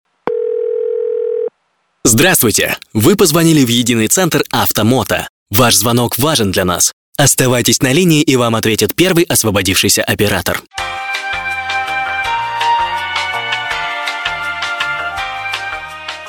Муж, Автоответчик/Средний